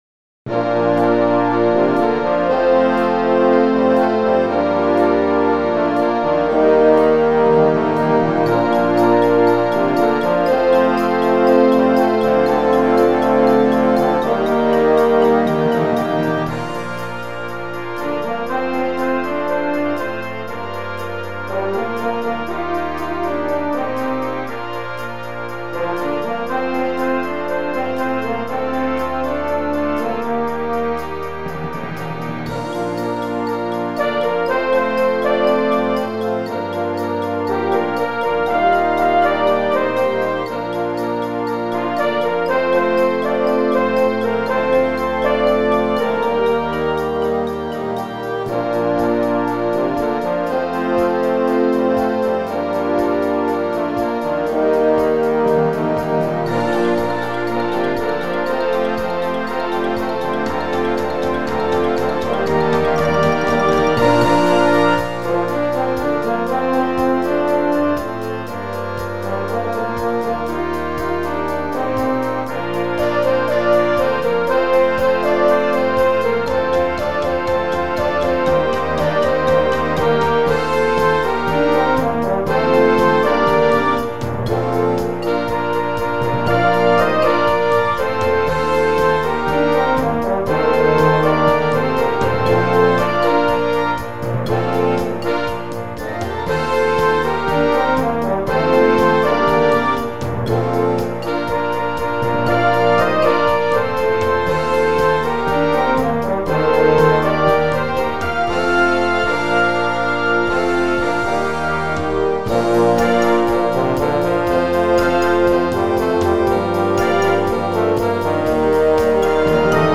Gattung: Moderner Einzeltitel für Blasorchester
Besetzung: Blasorchester